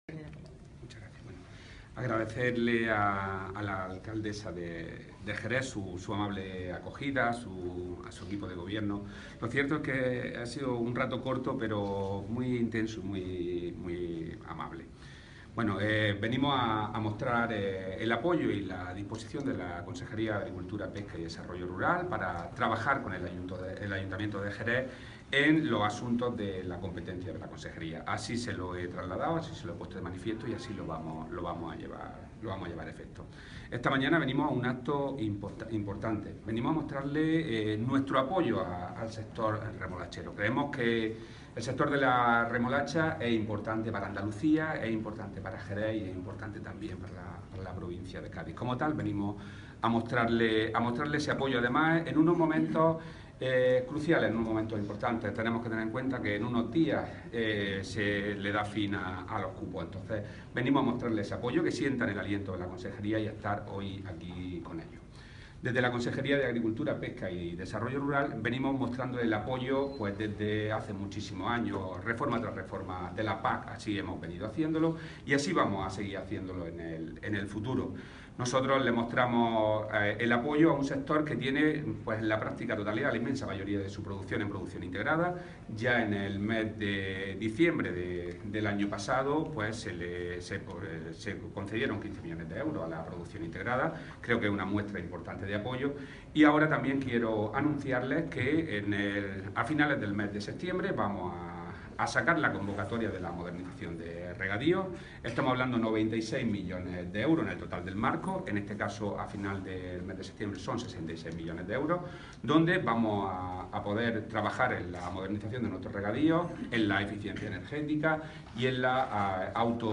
El consejero, en el acto de entrega del premio al Mejor Remolachero 2017.
Declaraciones consejero